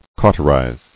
[cau·ter·ize]